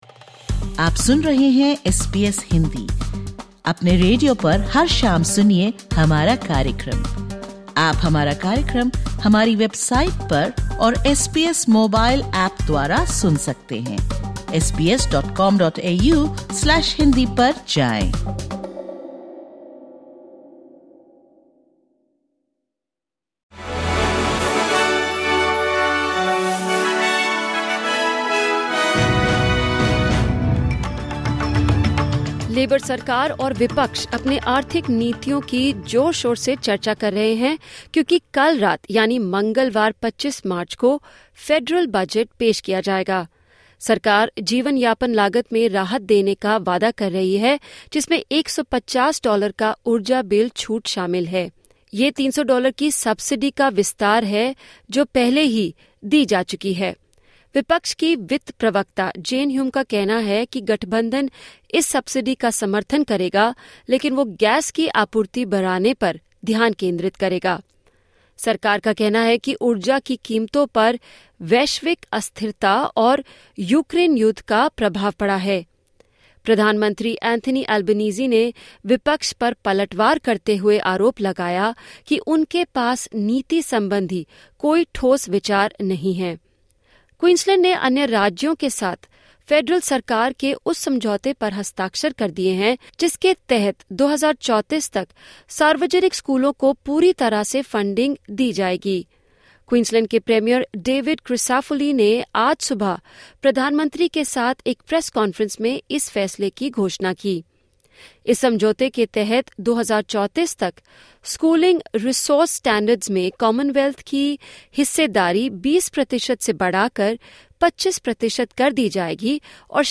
Listen to the top News of 24/03/2025 from Australia in Hindi.